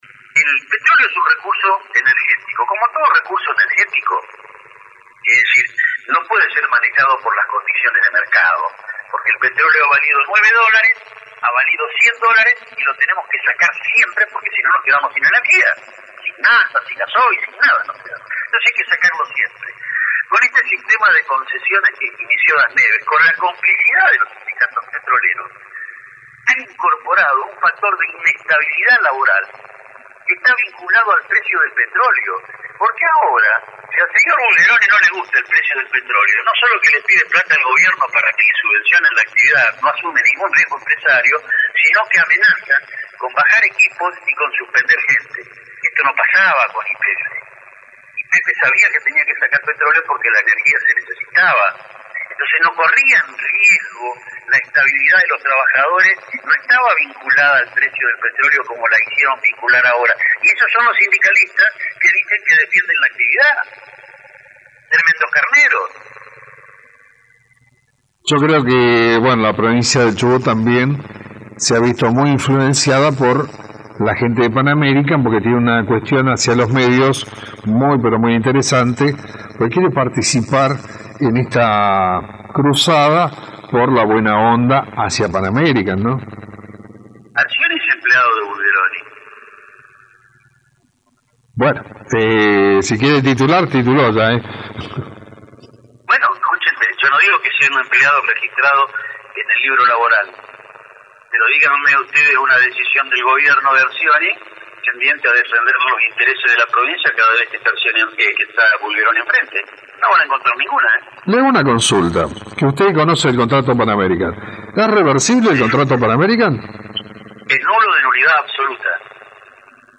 Asi lo menciono el abogado y dirigente radical Mario Cimadevilla en nuestro programa periodistico Rompe-Cabezas que se emite por la 89,5 Universo Radio de Comodoro Rivadavia de 13 a 15 hs.